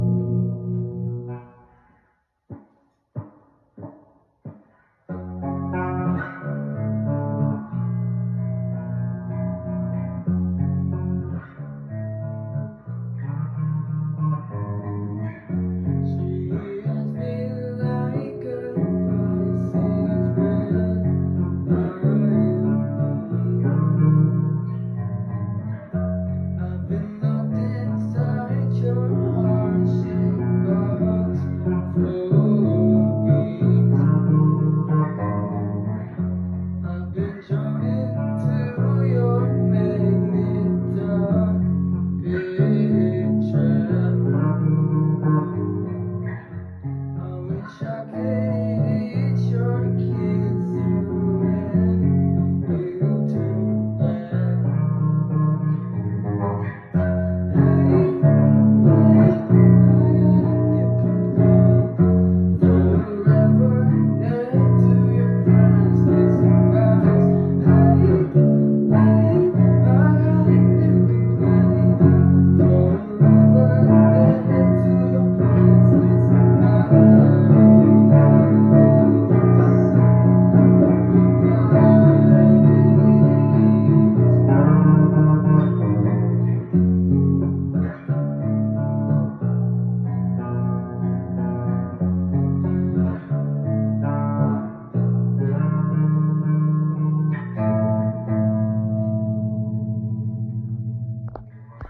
your singing is not bad, you are quite in tune